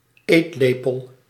Ääntäminen
Ääntäminen Tuntematon aksentti: IPA: /kɥi.jɛʁ/ Haettu sana löytyi näillä lähdekielillä: ranska Käännös Ääninäyte Substantiivit 1. lepel {m} 2. eetlepel {m} Suku: f .